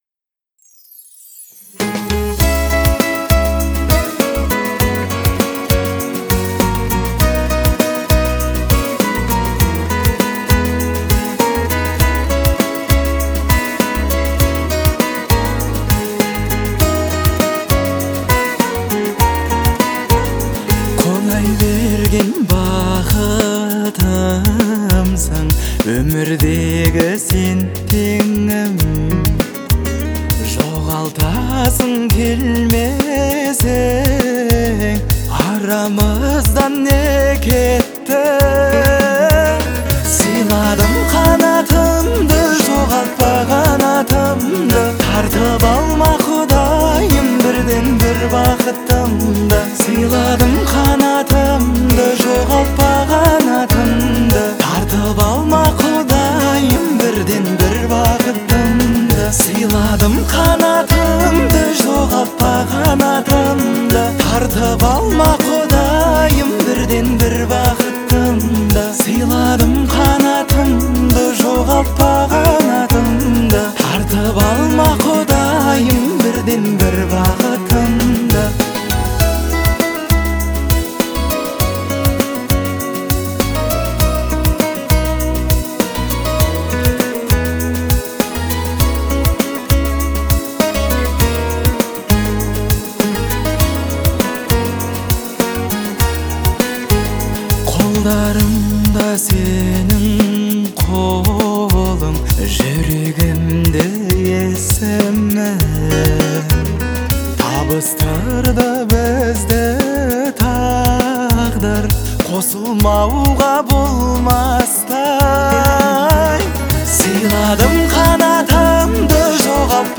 это трек в жанре казахского поп-фолка